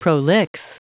Prononciation du mot prolix en anglais (fichier audio)
Prononciation du mot : prolix